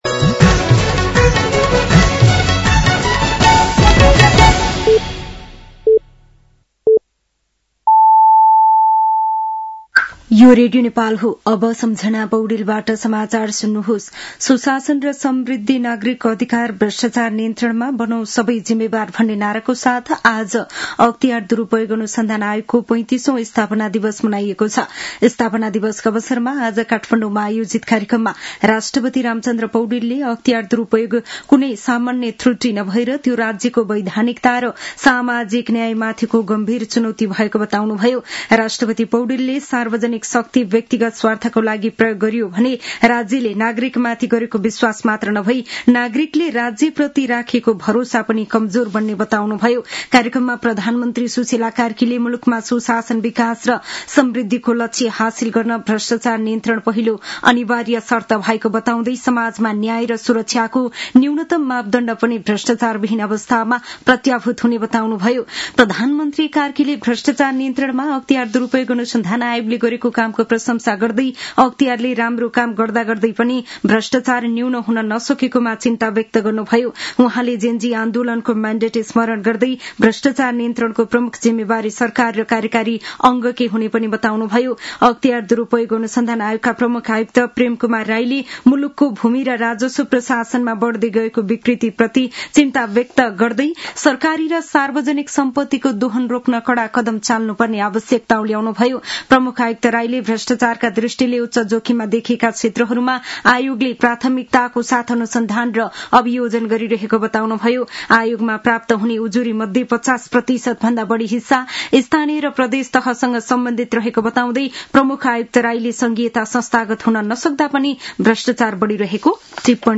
साँझ ५ बजेको नेपाली समाचार : २८ माघ , २०८२
5-pm-news-10-28.mp3